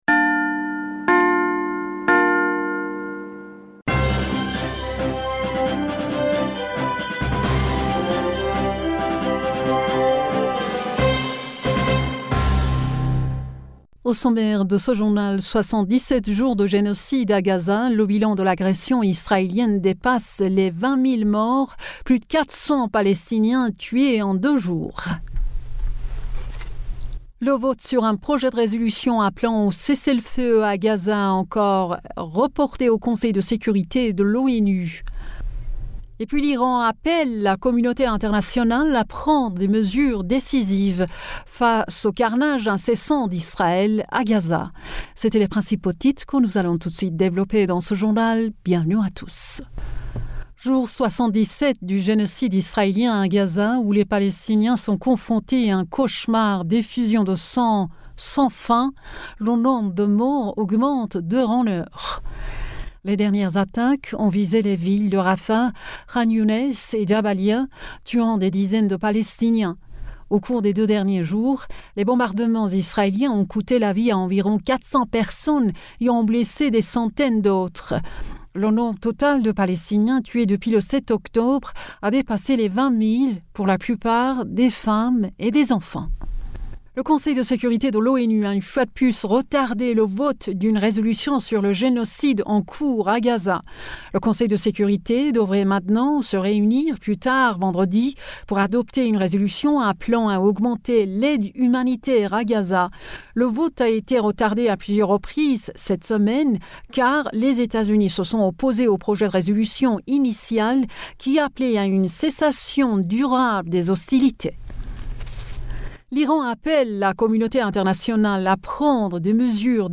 Bulletin d'information du 22 Decembre 2023